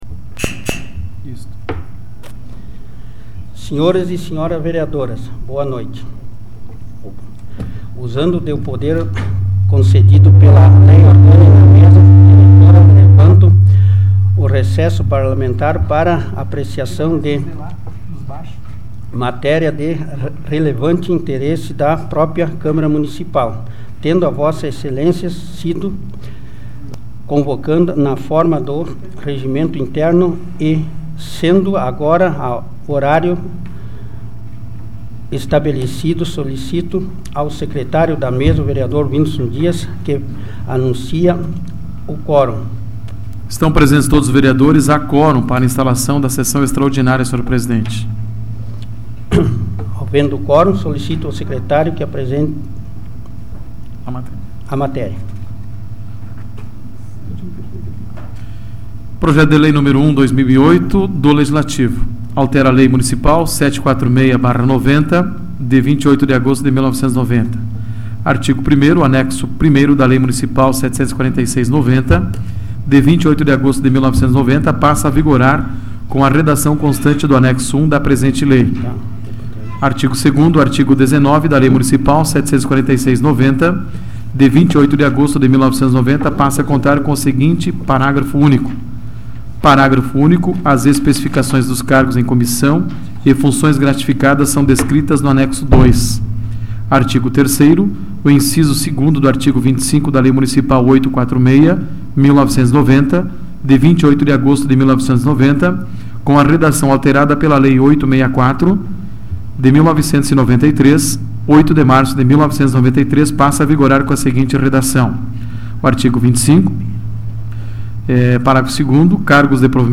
Áudio da 46ª Sessão Plenária Extraordinária da 12ª Legislatura, de 29 de janeiro de 2008